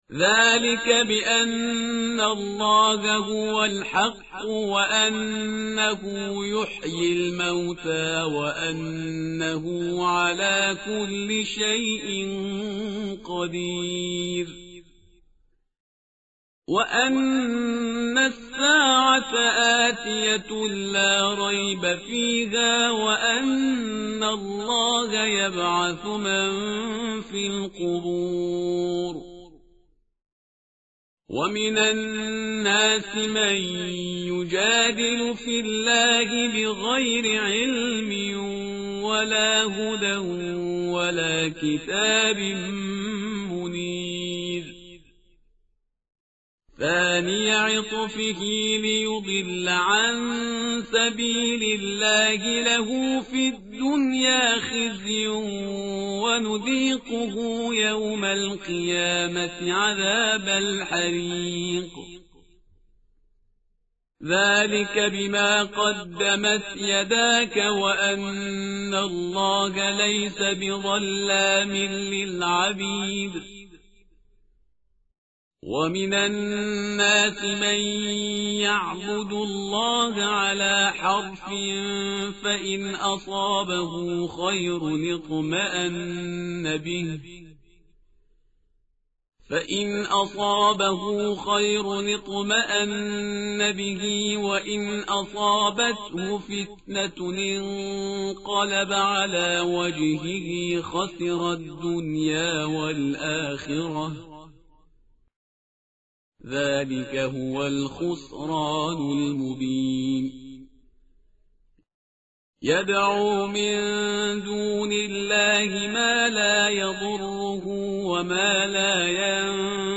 tartil_parhizgar_page_333.mp3